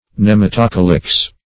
Search Result for " nematocalyx" : The Collaborative International Dictionary of English v.0.48: Nematocalyx \Nem`a*to*ca"lyx\, n.; pl.
nematocalyx.mp3